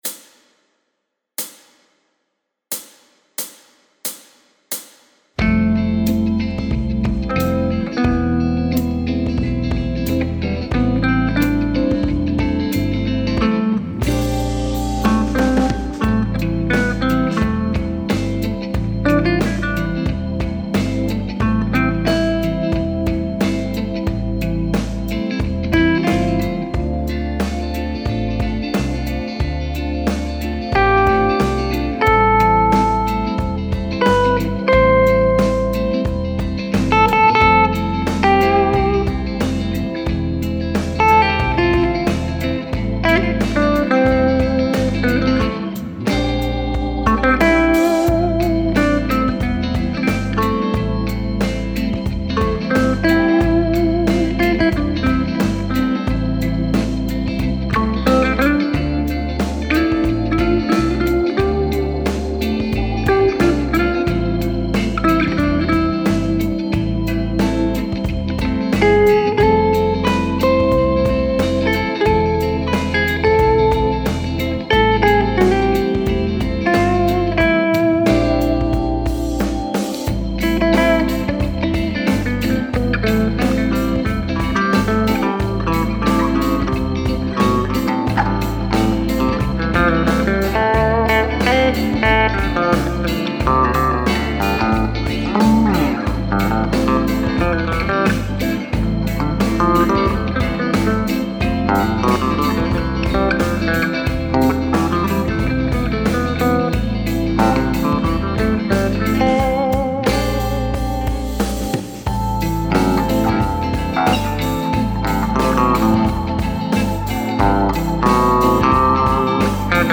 C major scale